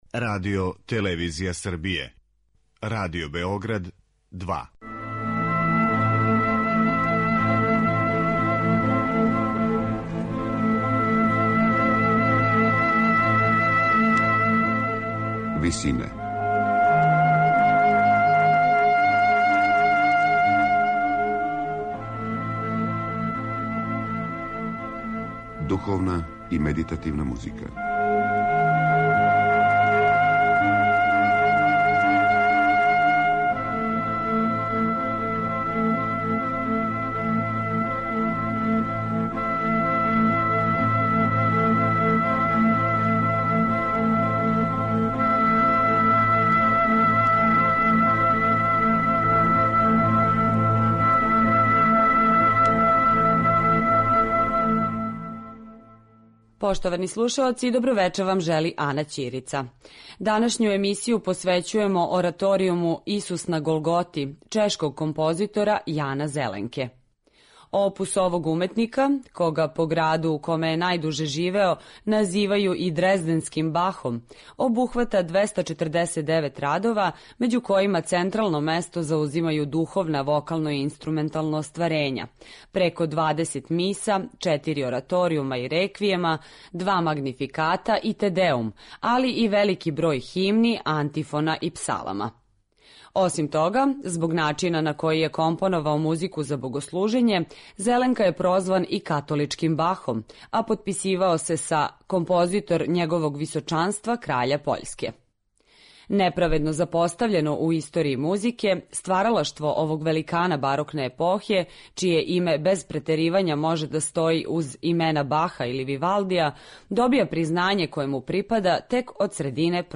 За разлику од већине ораторијума и пасија, целокупна „радња" овог суштински контемплативног остварења концентрисана је у једној јединој сцени у којој три Марије и Свети Јован чекају Исуса на Маслиновој гори, после његове осуде на смрт. Од многих композиционих поступака по којима је Зеленка препознатљив, у овом делу су најприсутнији примена високо колористичког третмана хроматике, као и замисли које су „разигране" по читавом оркестарском парту, понекад унисоно или кроз необичне ритмичке и мелодијске мотиве, који највероватније потичу из чешке фолклорне музике.